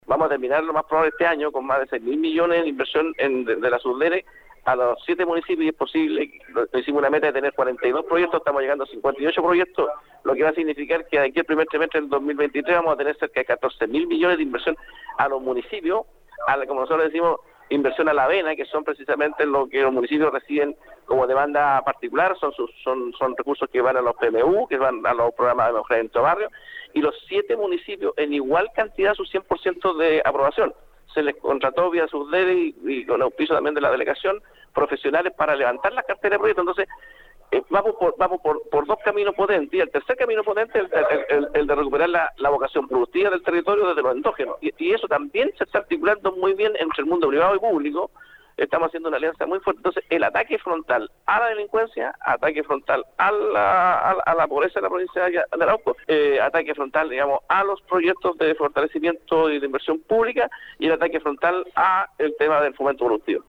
La autoridad provincial, en entrevista con Nuestra Pauta, destacó tanto el trabajo coordinado entre las policías y el Ministerio Público en la persecución de delitos como el vinculo con municipios y organizaciones sociales en materias de seguridad pública.